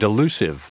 Prononciation du mot delusive en anglais (fichier audio)
Prononciation du mot : delusive